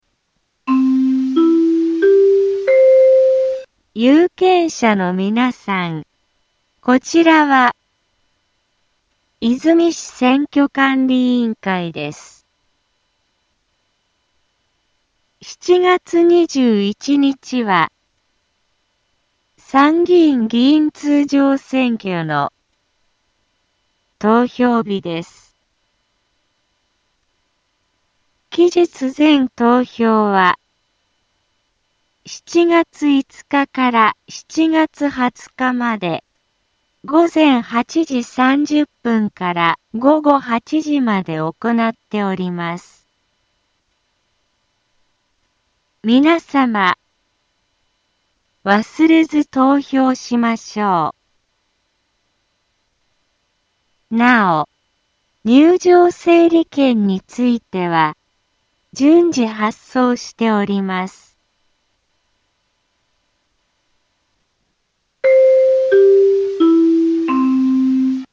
Back Home 災害情報 音声放送 再生 災害情報 カテゴリ：通常放送 住所：大阪府和泉市府中町２丁目７−５ インフォメーション：有権者のみなさん こちらは、和泉市選挙管理委員会です。 7月21日は、参議院議員通常選挙の、投票日です。